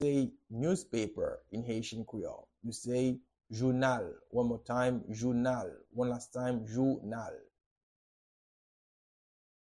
How-to-say-Newspaper-in-Haitian-Creole-Jounal-pronunciation-by-a-native-Haitian-teacher.mp3